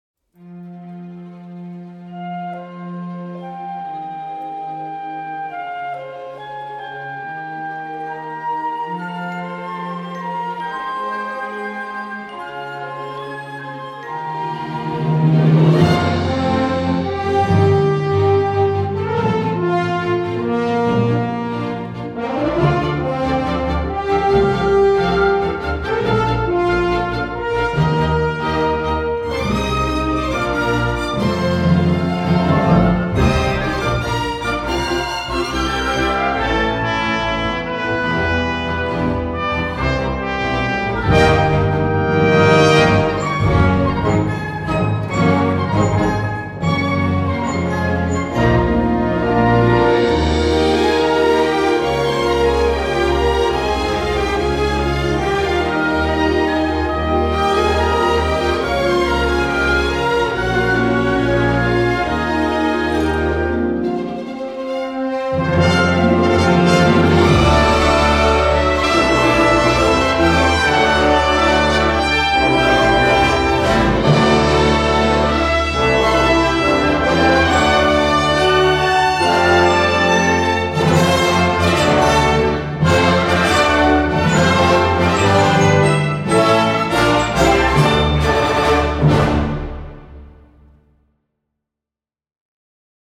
Fantasy Adventure: Heroic, Uplifting, Grand, Bright